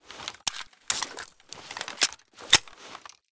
reload_full.ogg